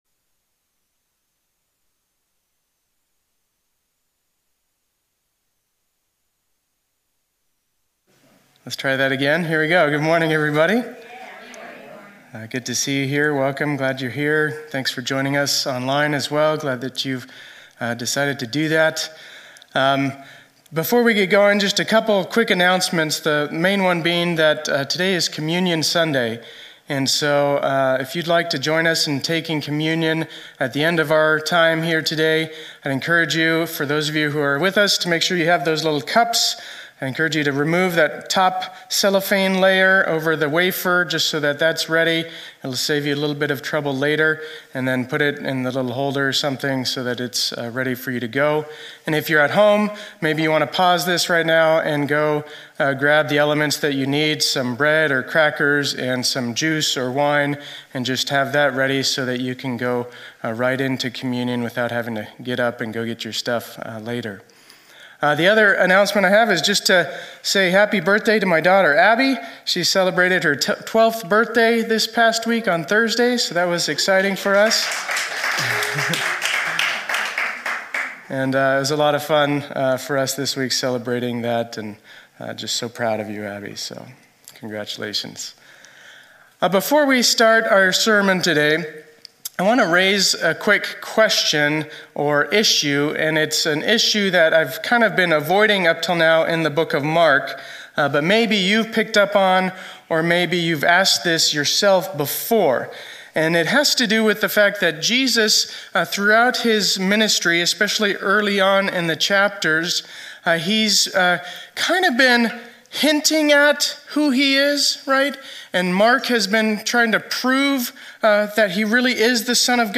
2021-02-07 Sunday Service